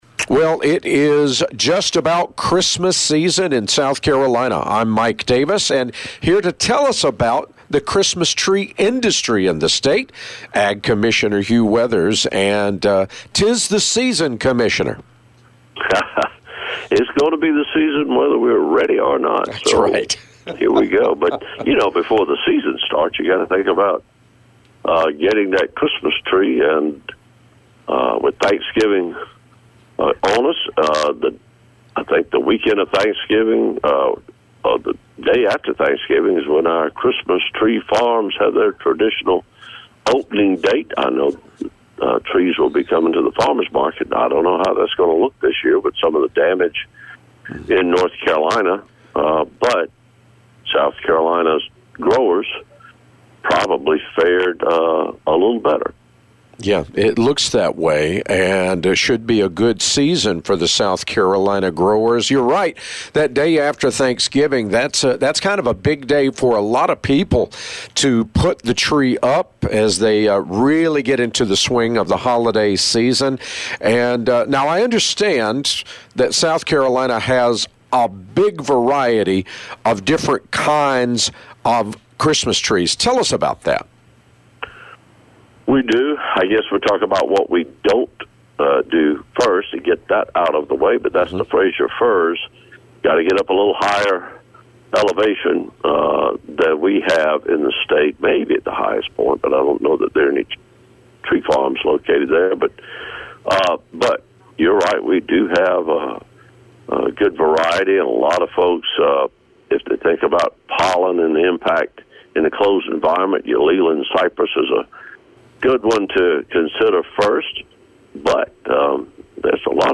Every week Commissioner Hugh Weathers talks about what’s going on with agriculture in our state with The Southern Farm Network.
Check back each week to hear the latest news, or you can listen to past interviews here.